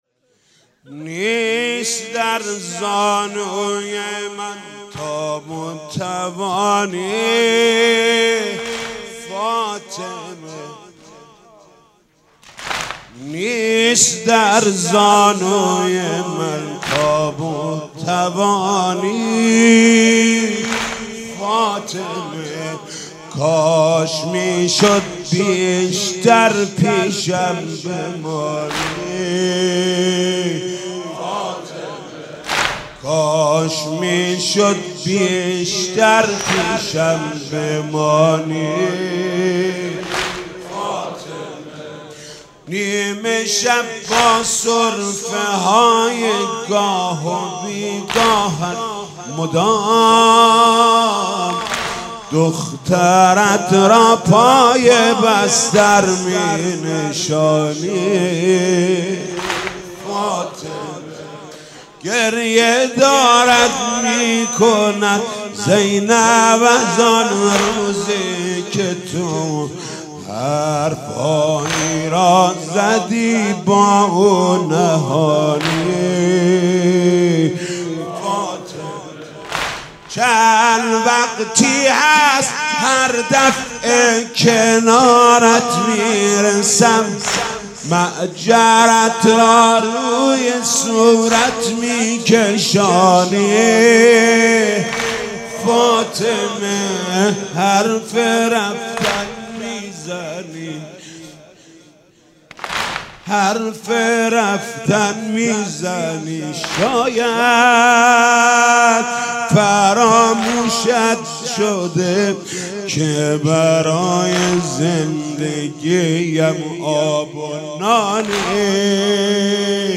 مداحی و نوحه
به مناسبت ایام فاطمیه
[واحد]